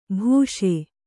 ♪ bhūṣe